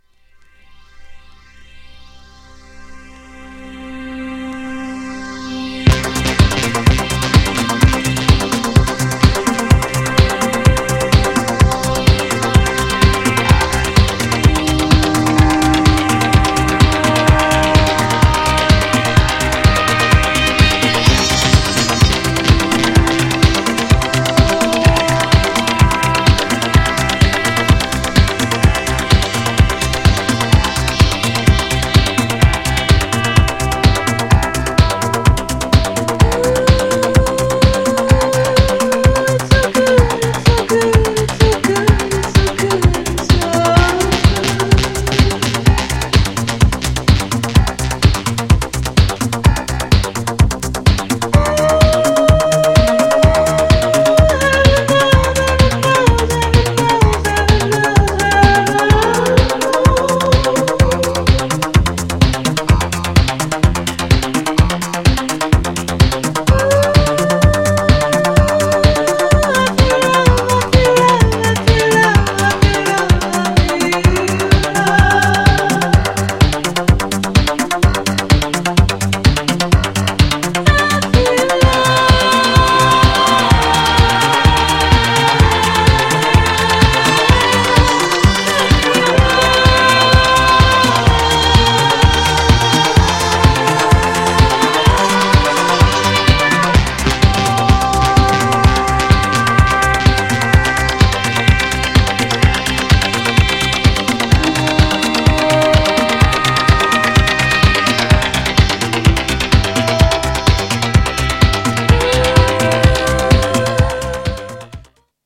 ジャンル：ディスコ